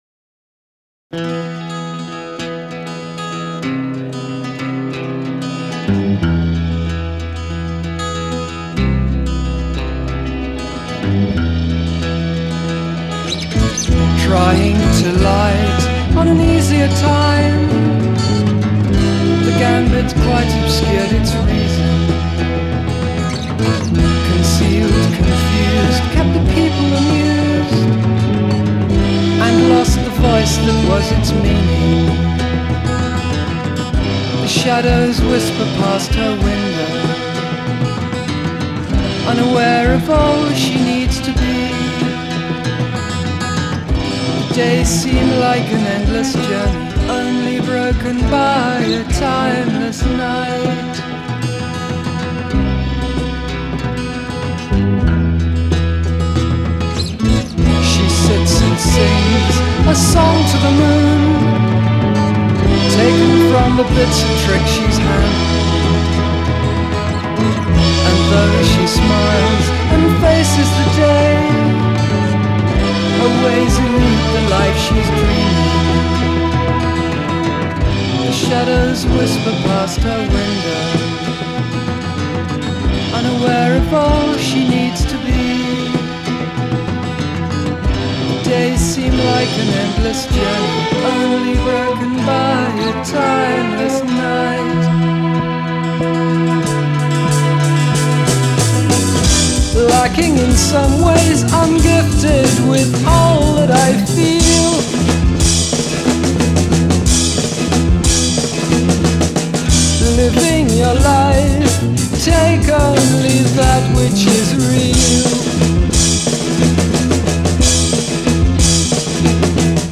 acoustic guitar / electric guitar / lead vocals
bass guitar
drums / percussion
cello / piano / harmonium / backing vocals
violin / backing vocals
Recorded at Sound Techniques Studios